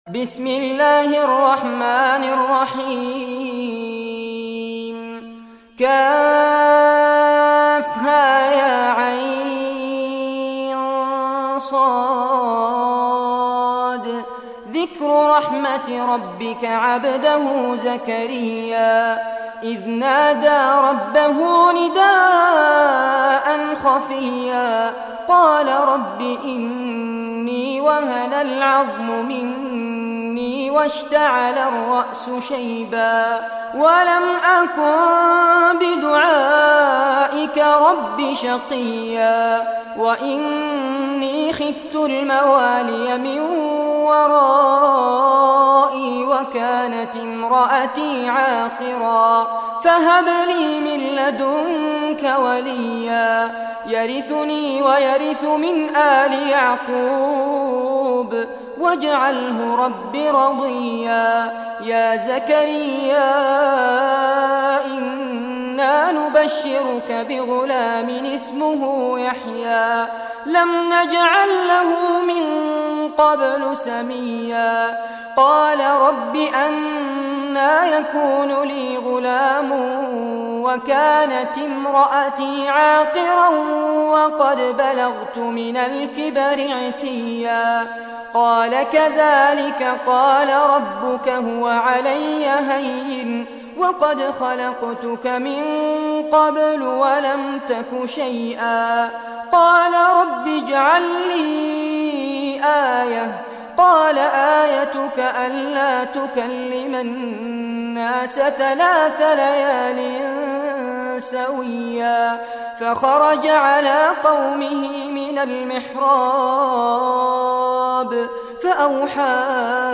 Surah Maryam Recitation by Sheikh Fares Abbad
Surah Maryam, listen or play online mp3 tilawat / recitation in Arabic in the beautiful voice of Sheikh Fares Abbad.